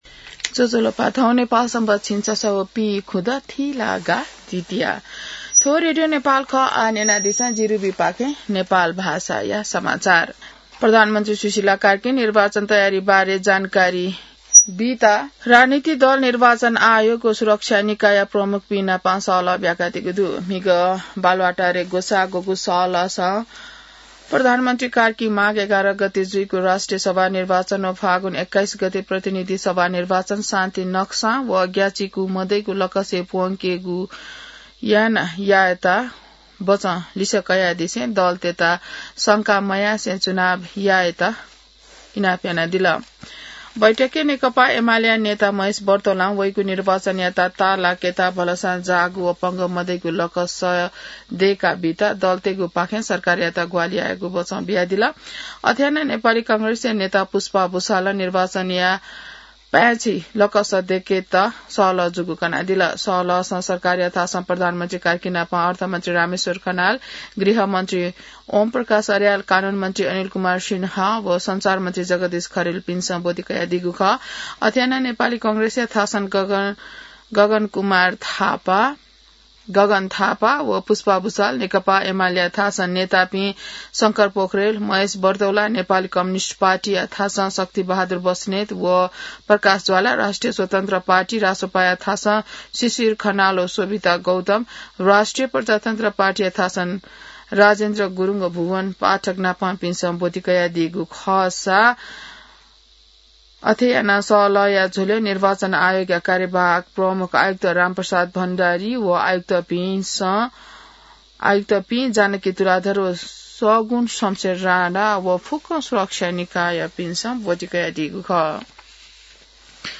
नेपाल भाषामा समाचार : २० मंसिर , २०८२